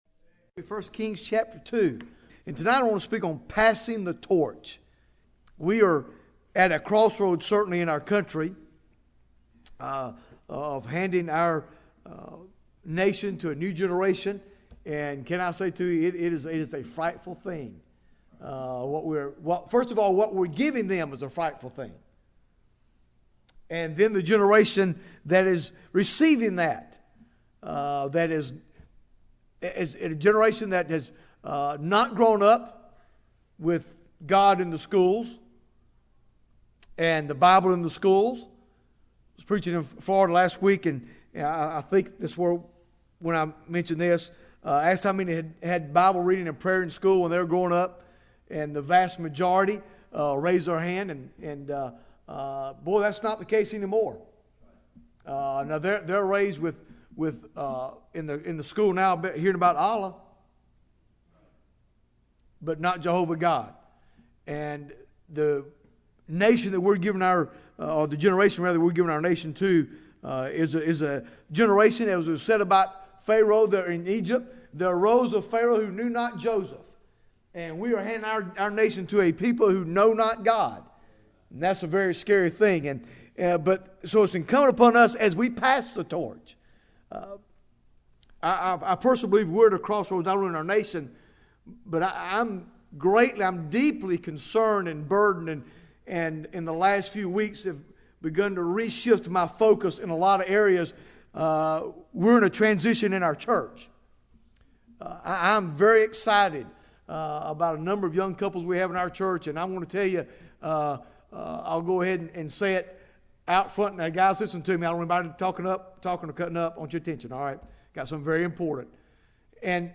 Bible Text: I Kings 2 | Preacher